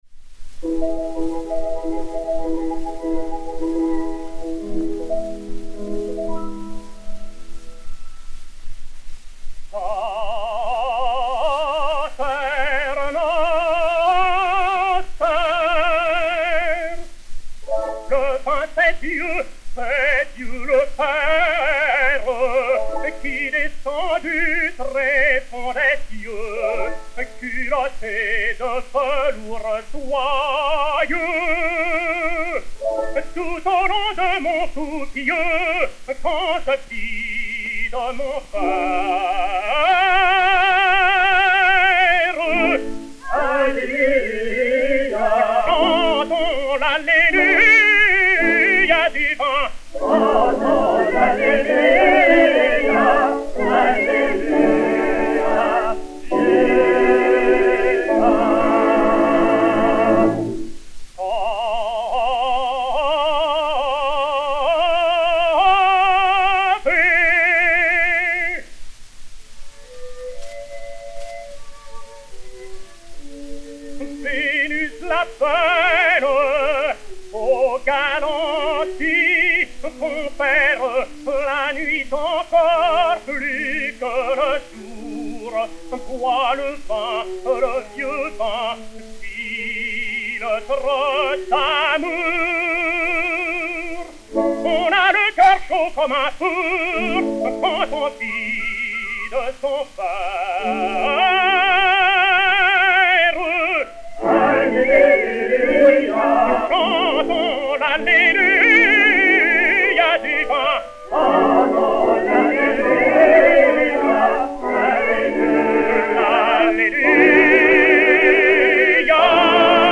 Chœurs et Orchestre